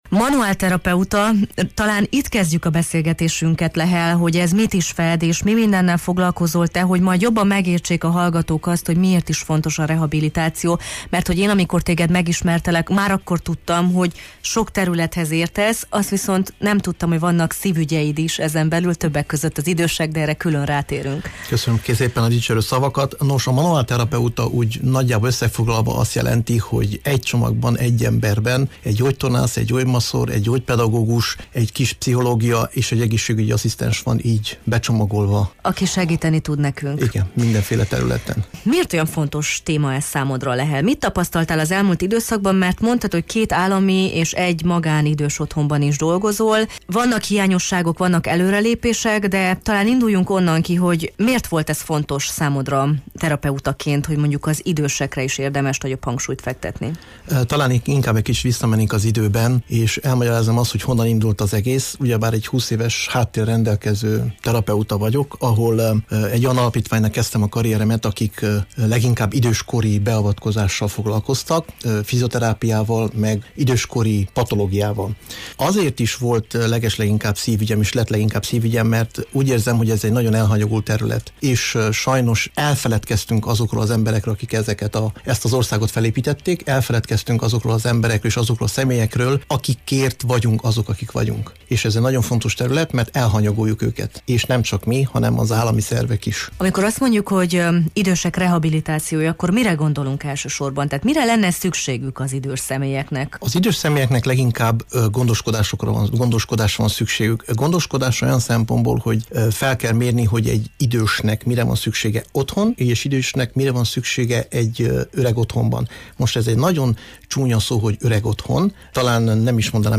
manuál terapeutát kérdeztük a téma kapcsán a Jó reggelt, Erdély!-ben: